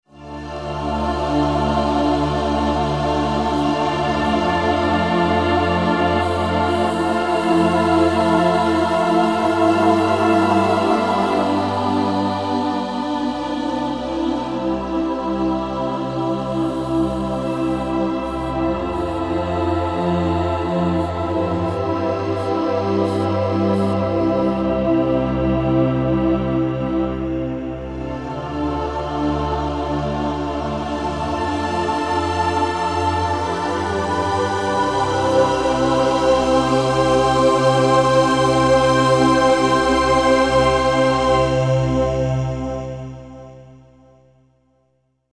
A large collection of mystical Choir and Vocal sounds that will take you on a magical journey.